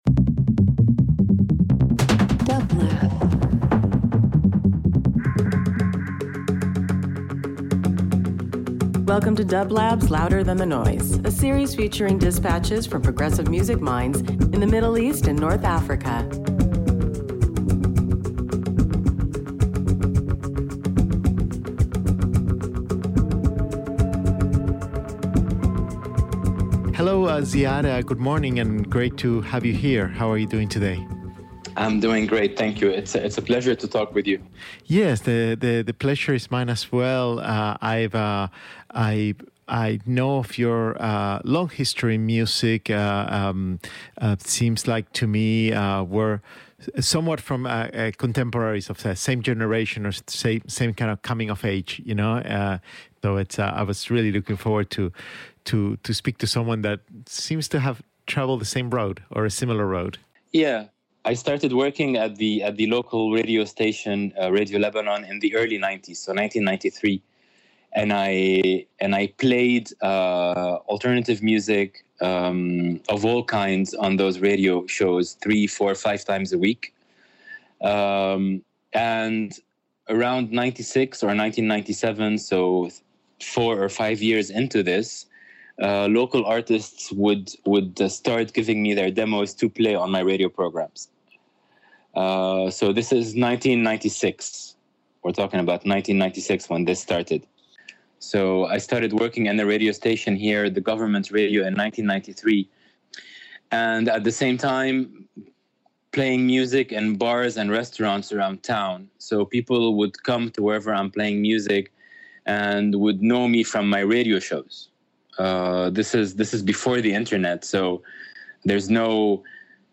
dublab Radio presents Louder than the Noise: Dispatches from Progressive Music Minds, Makers, and Communities in the Middle East and North Africa. Our guests come from across the music world, including artists, engineers, producers, journalists, radio hosts, label owners, and more.
Interview